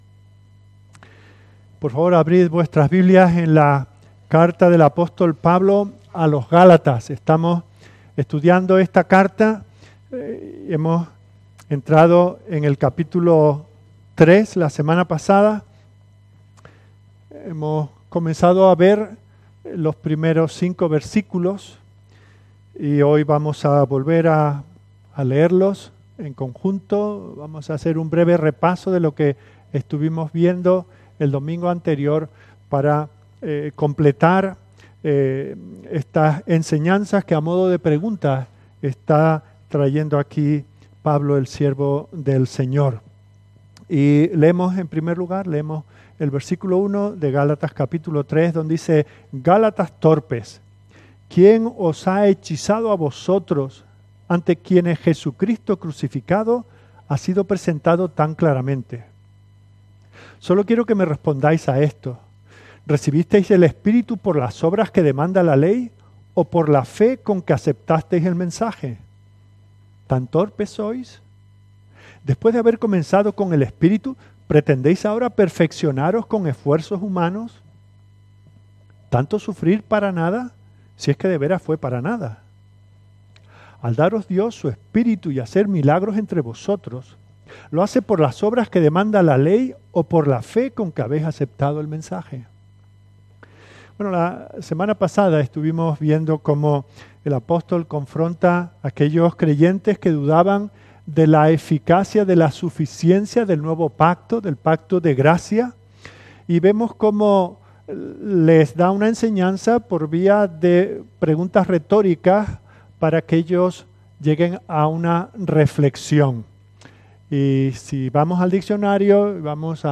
Comentario Bíblico Expositivo